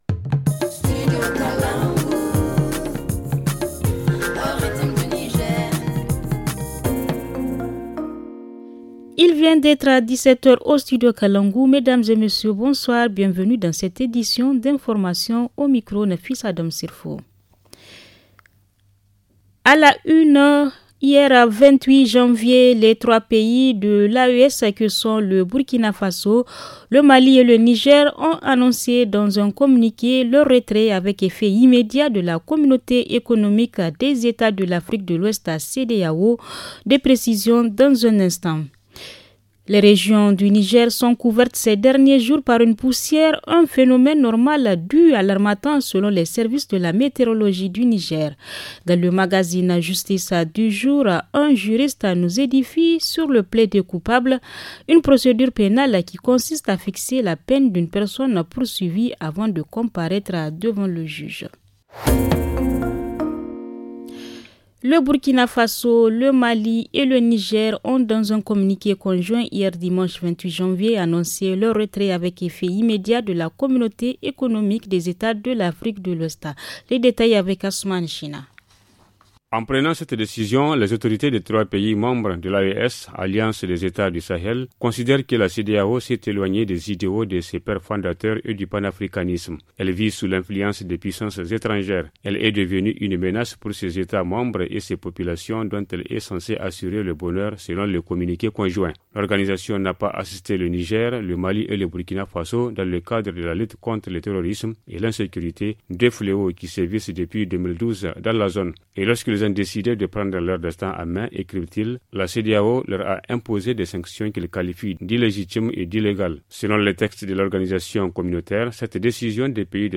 Le journal du 29 janvier 2024 - Studio Kalangou - Au rythme du Niger